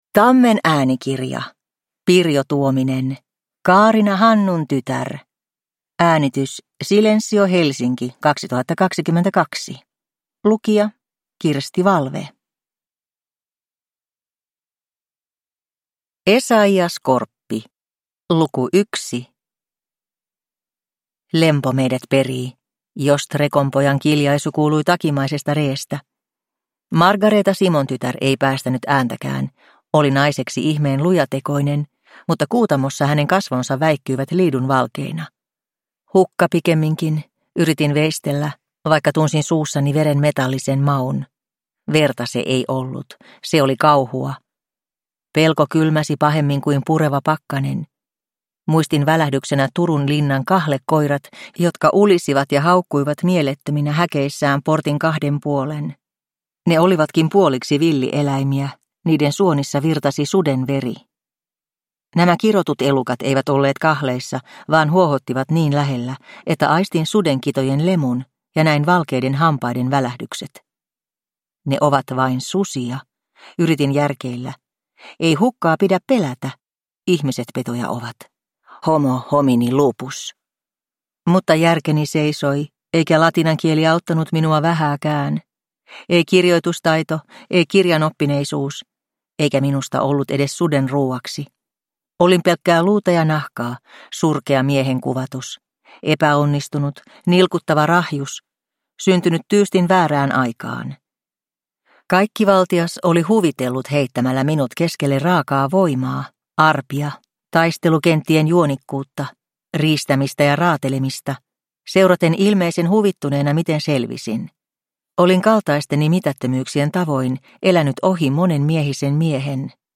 Kaarina Hannuntytär – Ljudbok – Laddas ner